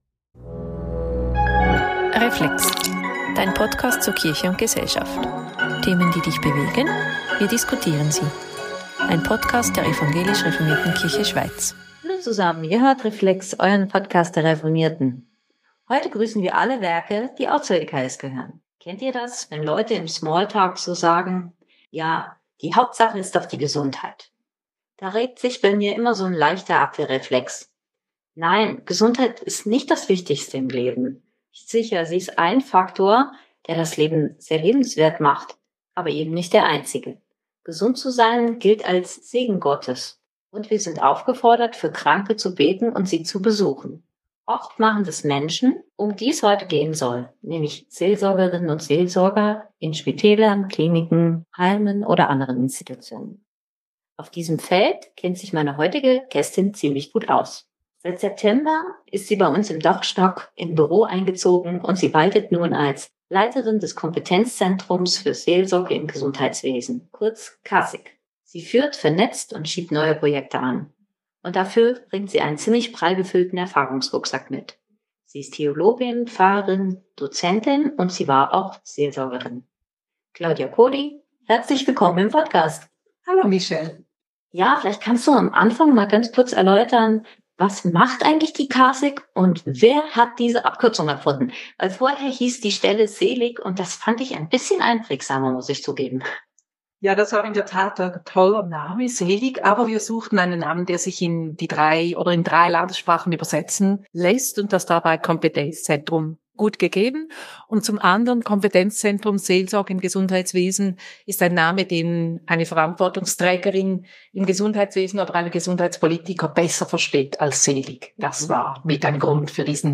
PS: Dieses Mal hört ihr Reflex nicht in der gewohnten Tonqualität. Uns ist bei der Aufnahme ein Fehler unterlaufen.